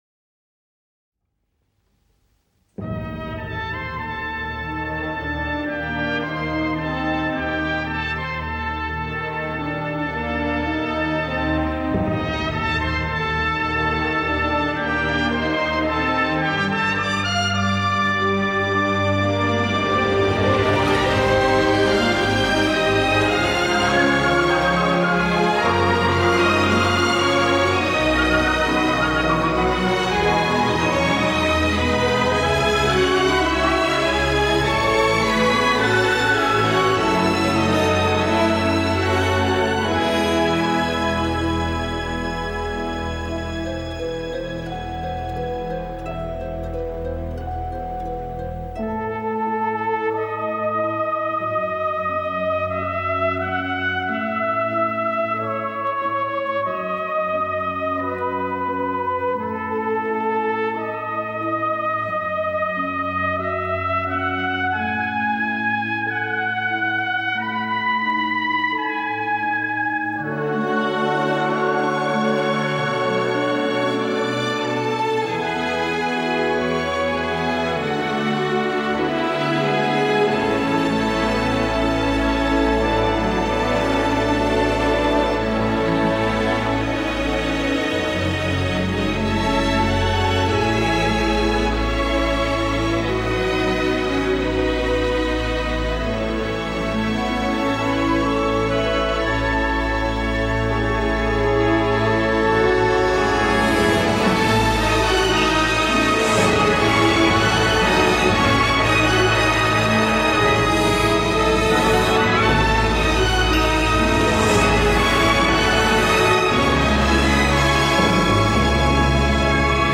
Un poème orchestral voluptueux, beau et richement évocateur.